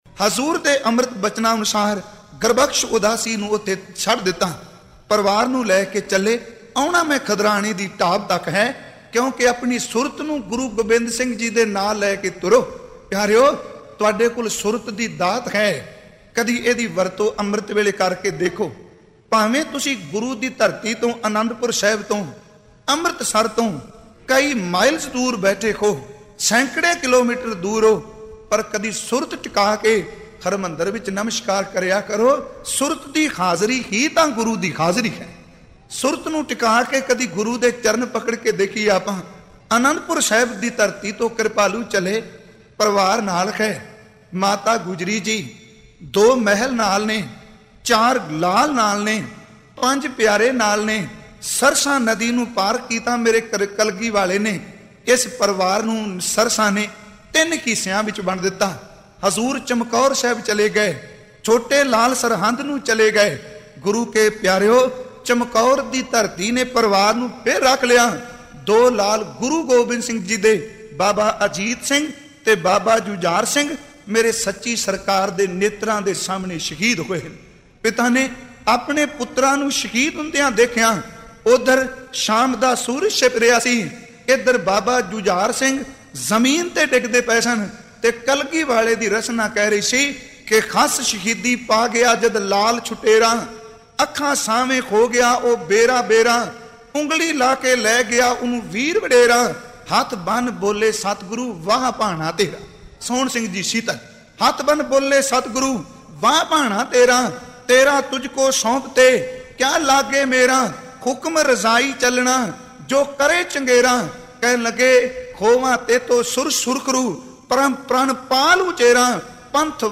Saka Mukatsar Katha